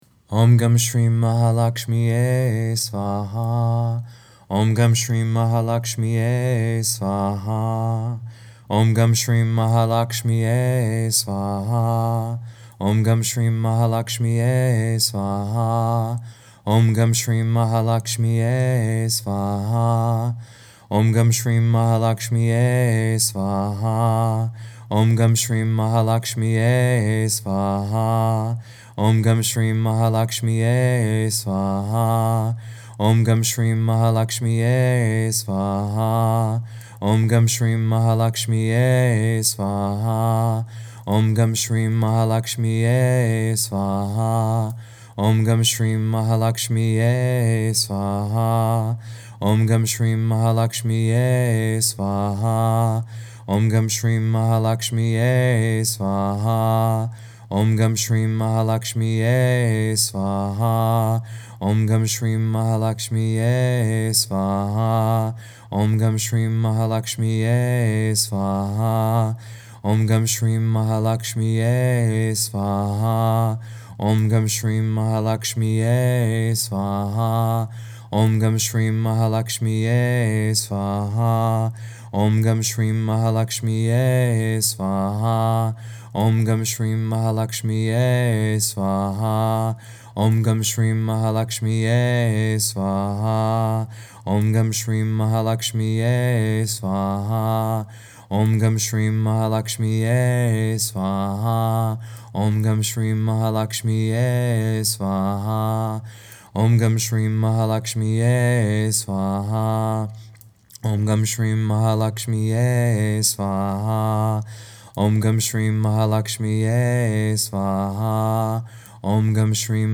In any case, here’s a download recording of me chanting the mantra 108 times.
LAKSHMI-GANESH-MANTRA-108.mp3